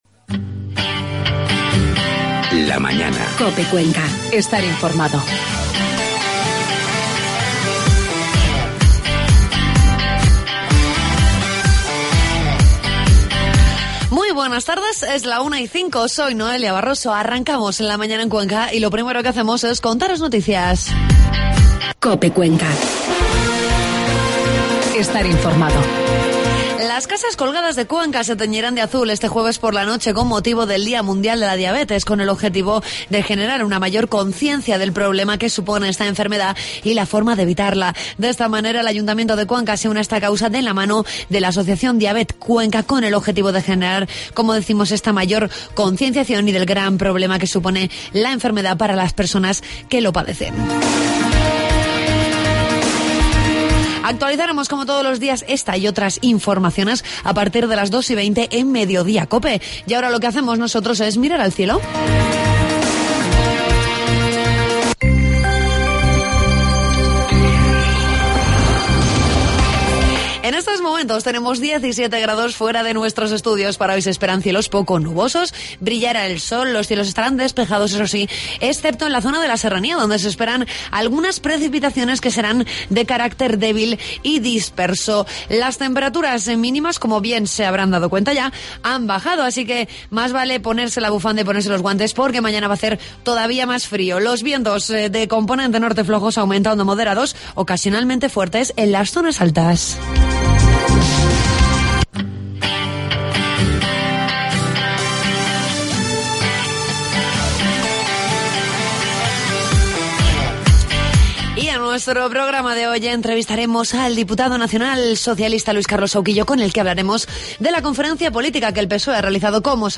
Entrevistamos al diputado nacional socialista, Luis Carlos Sahuquillo.